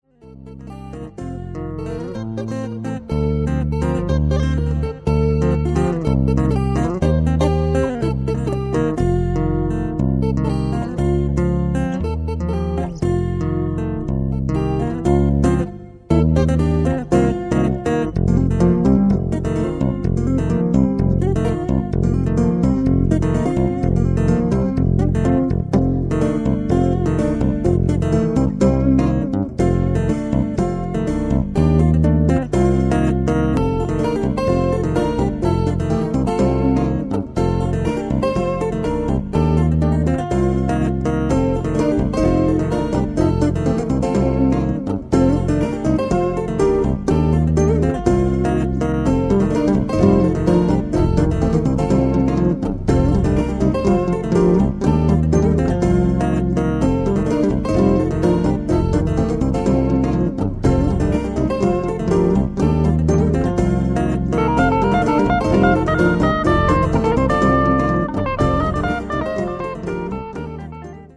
Upbeat piece that turns into a loop.